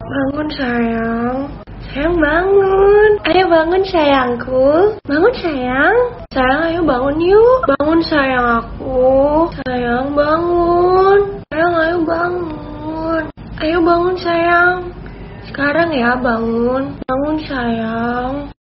Kategori: Nada dering
Yuk, download nada dering "Bangun Sayang, Ayo Yuk" versi suara wanita yang lagi viral di TikTok! Nada ini cocok banget buat alarm sahur atau sekadar pengingat bangun pagi biar gak telat.